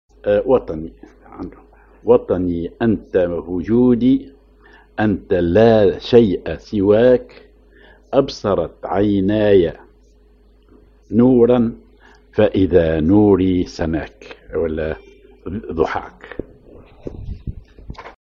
Maqam ar مزموم
genre نشيد